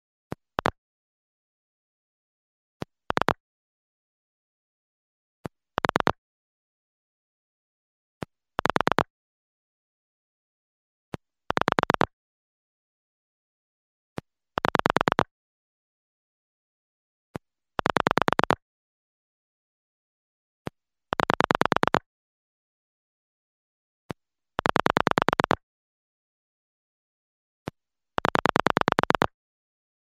На этой странице собраны звуки набора номера в телефоне — от винтажных импульсных гудков до современных тональных сигналов.
Звук соединения с абонентом на старом телефоне